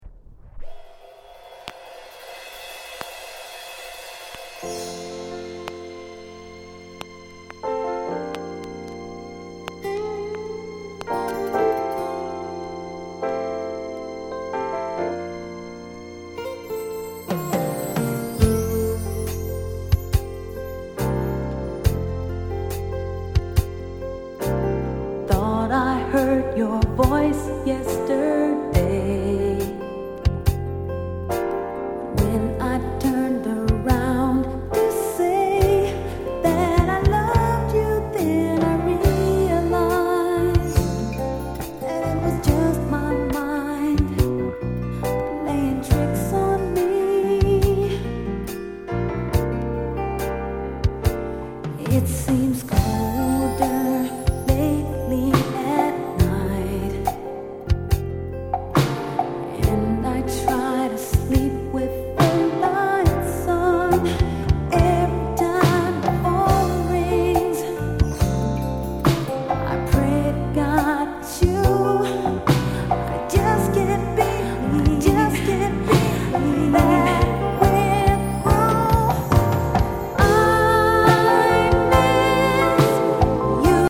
80��S SOUL